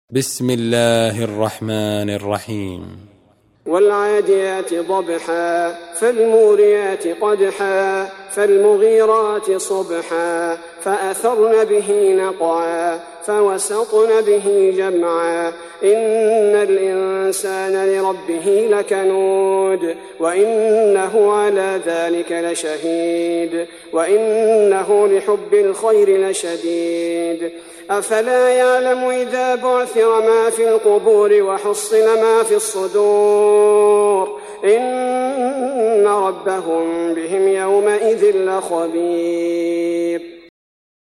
Surah Sequence تتابع السورة Download Surah حمّل السورة Reciting Murattalah Audio for 100. Surah Al-'Adiy�t سورة العاديات N.B *Surah Includes Al-Basmalah Reciters Sequents تتابع التلاوات Reciters Repeats تكرار التلاوات